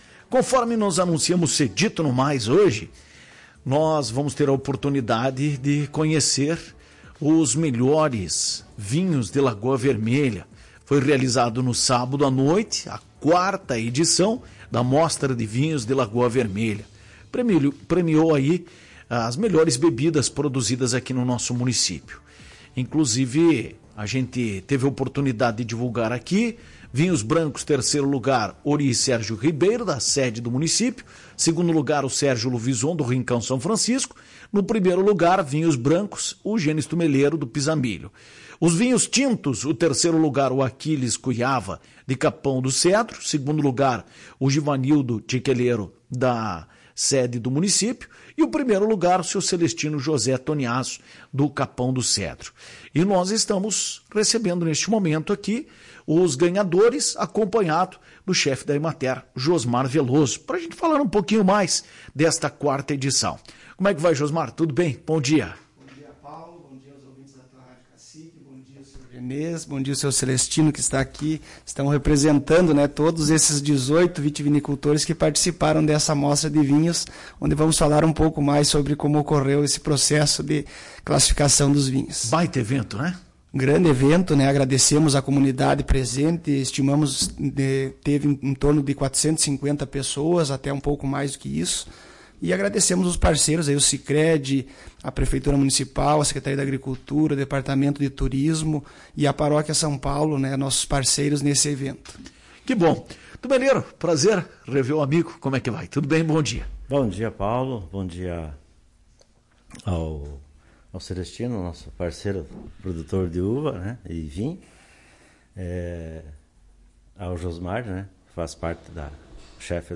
responsáveis pela produção dos melhores vinhos de Lagoa Vermelha concederam entrevista à Tua Rádio Cacique e celebram o resultado.